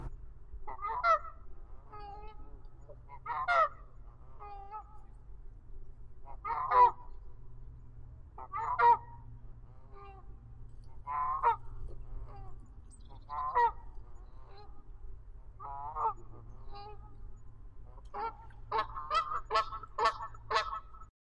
描述：sony ecmms907,sony mindisc;一群大雁起飞，音响有点不平衡。
Tag: fieldrecording greylag